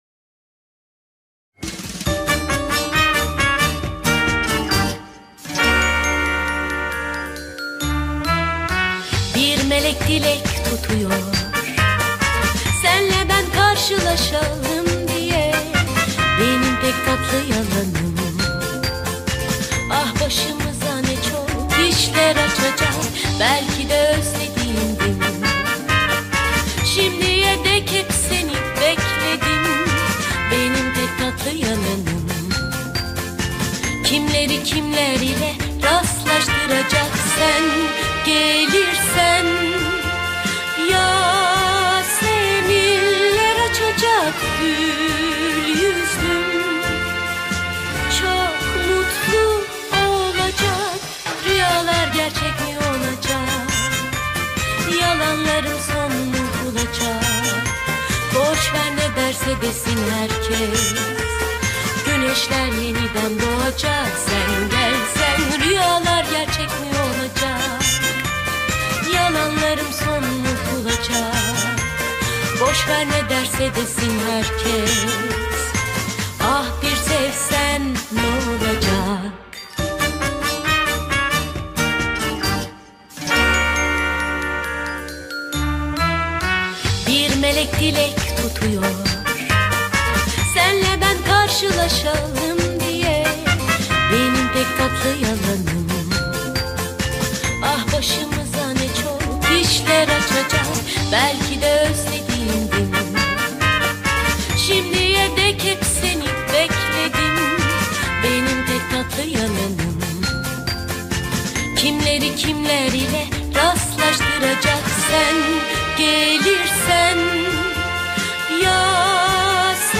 dizi müziği, mutlu neşeli eğlenceli fon müziği.